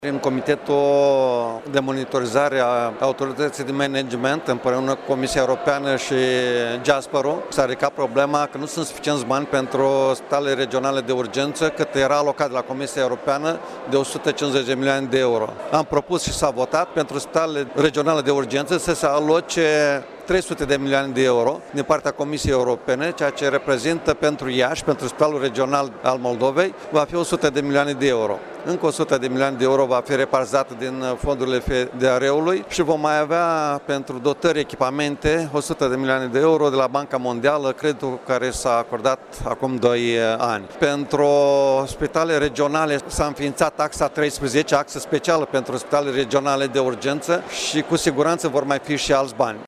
Maricel Popa a mai spus că fiecare spital va mai beneficia de încă o sută de milioane de euro pentru dotări cu aparatură, printr-un împrumut guvernamental făcut la Banca Mondială: